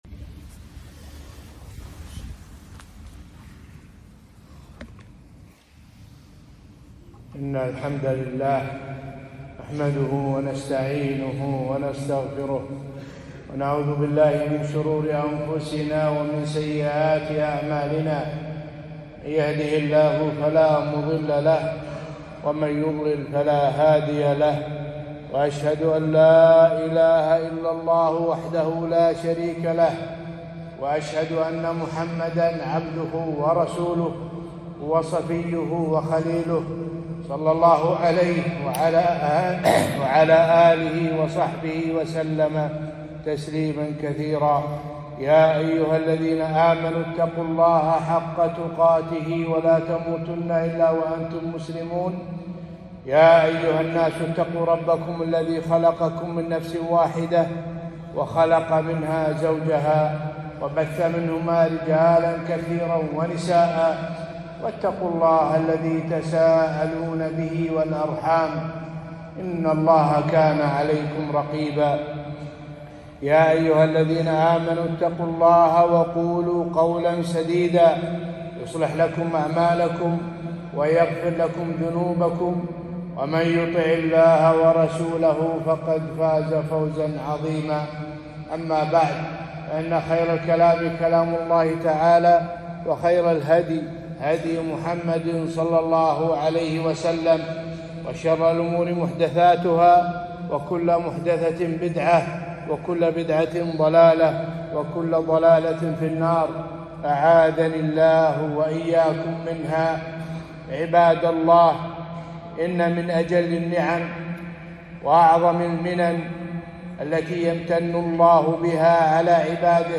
خطبة - نعمة السكن والبيت والمأوى